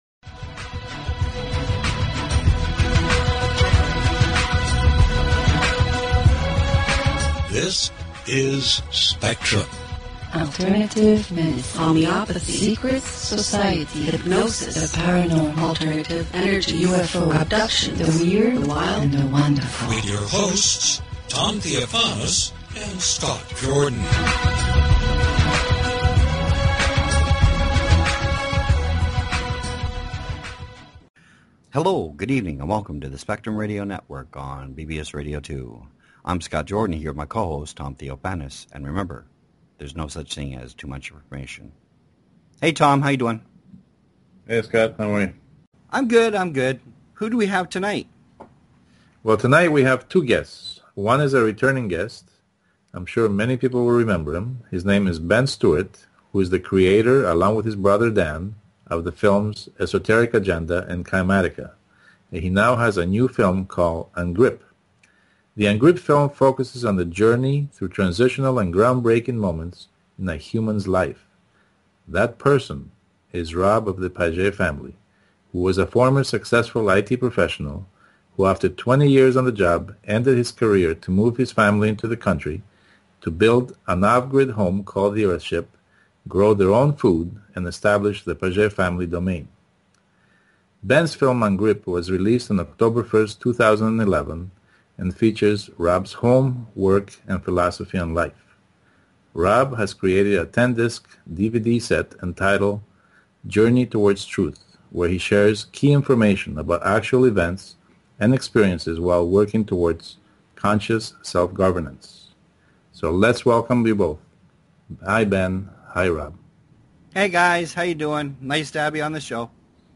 Talk Show Episode, Audio Podcast, Spectrum_Radio_Network and Courtesy of BBS Radio on , show guests , about , categorized as